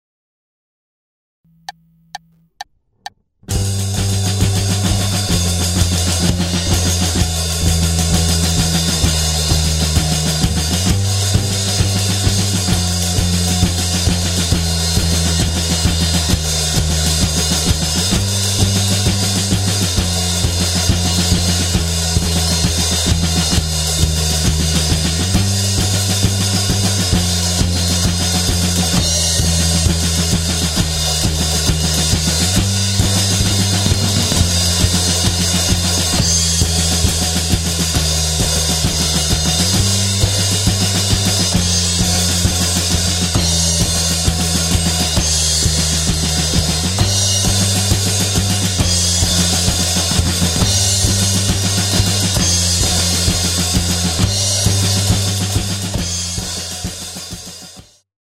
backing track for guitar solo